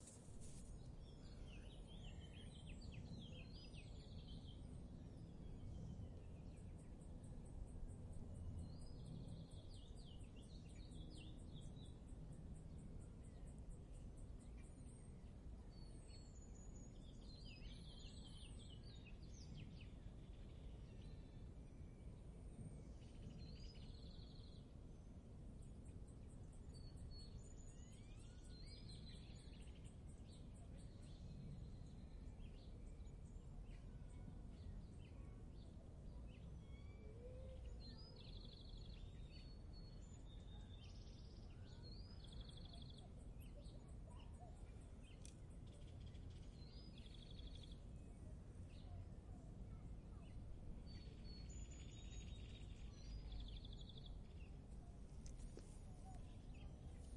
自然界 " 夜晚的蟋蟀和狗
描述：NIGHT蟋蟀和狗用变焦记录
标签： 乱叫 晚上 动物 蟋蟀 性质 现场记录
声道立体声